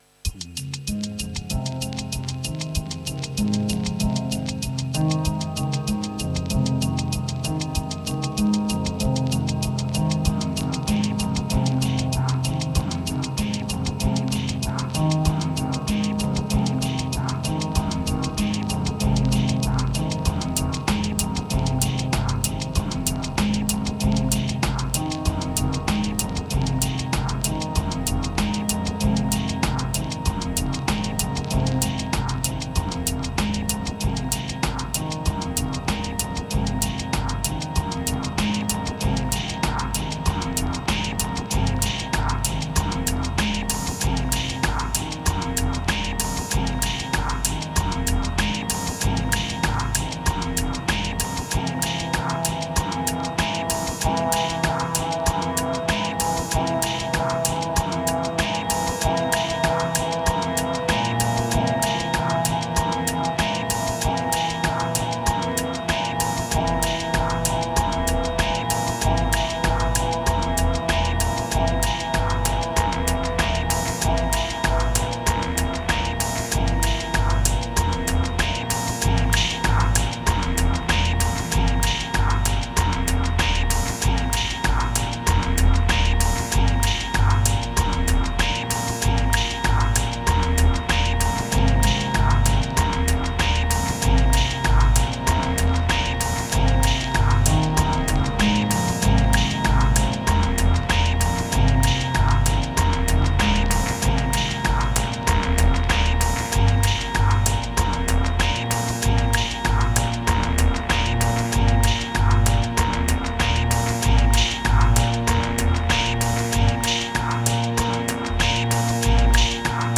Ambient Wax Ladder Moods Horizontal Deep Hopeless Static